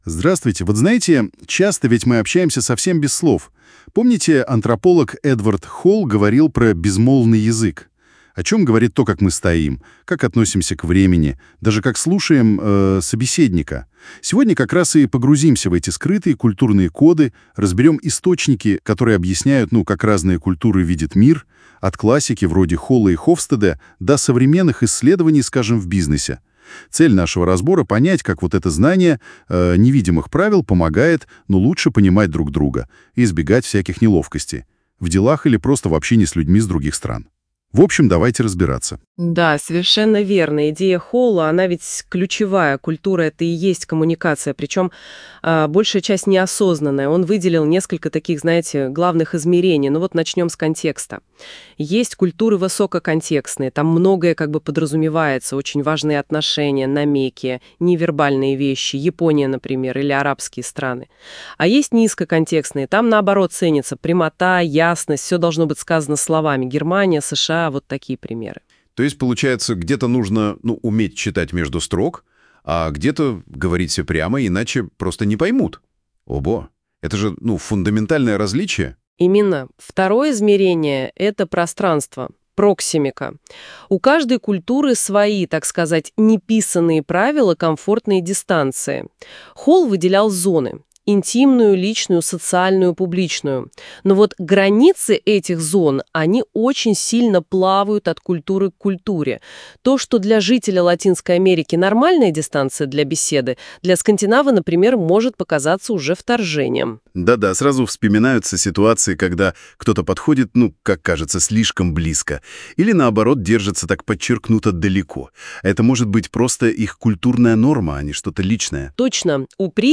Google NotebookLM из заметки про национальную культуру создает подкаст